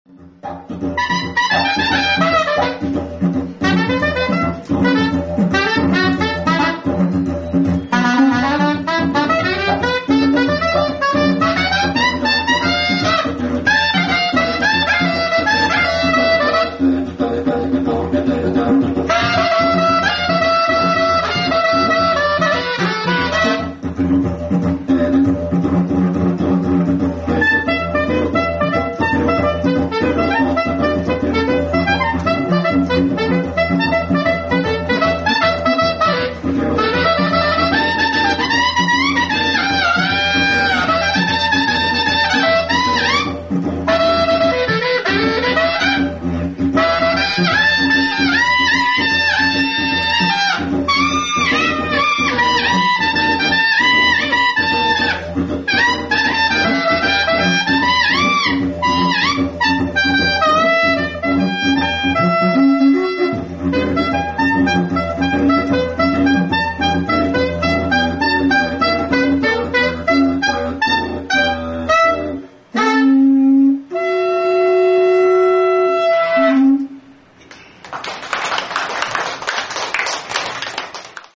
LIVE
LIVE&BAR Desperado
SAX
ディジュリドゥ